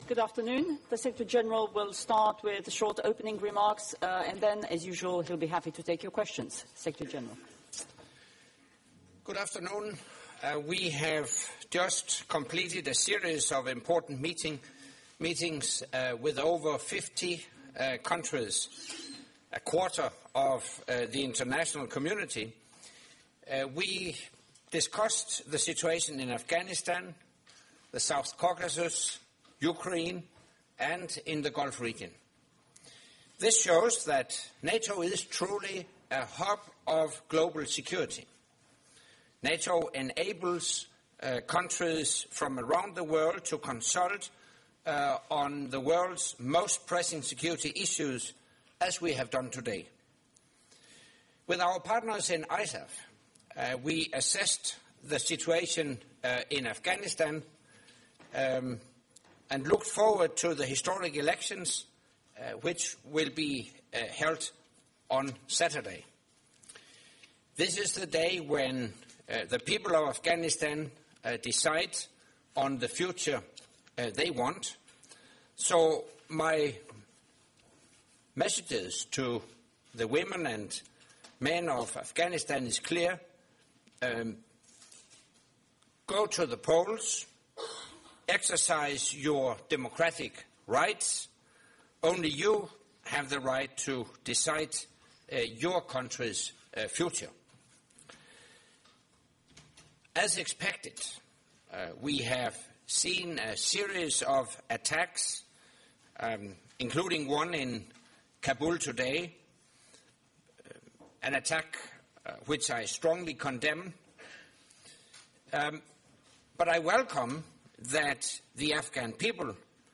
Final press conference by NATO Secretary General Anders Fogh Rasmussen following the second day of meetings of NATO Ministers of Foreign Affairs